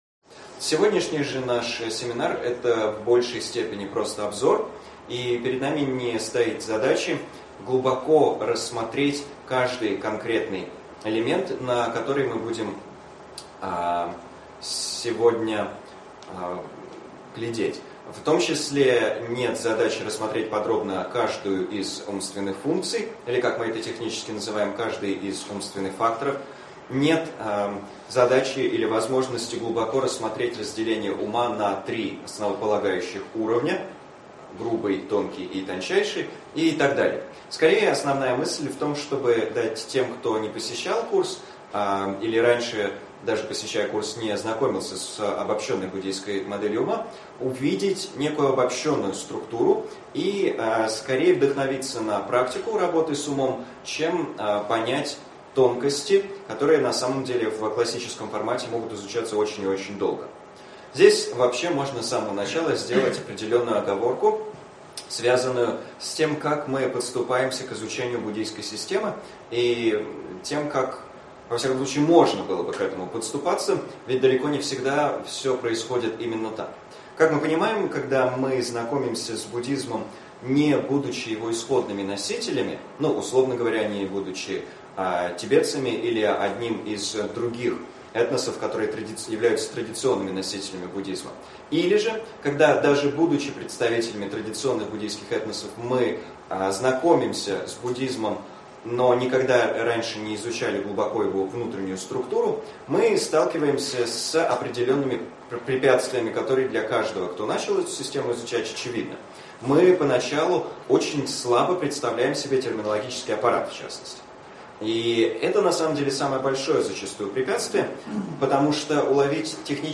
Аудиокнига Введение в буддийскую модель ума. Часть 1 | Библиотека аудиокниг